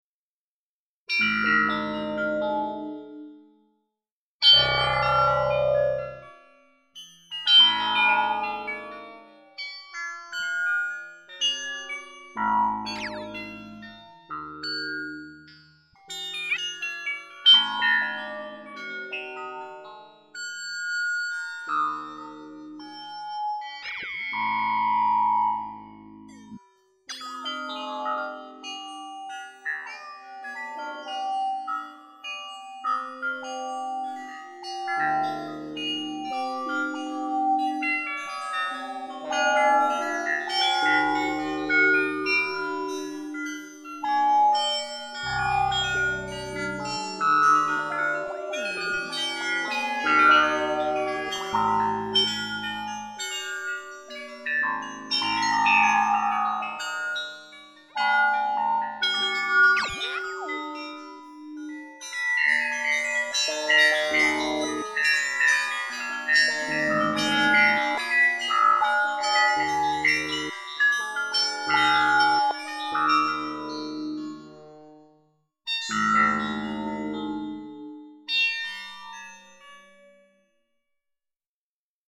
Solo Improvisations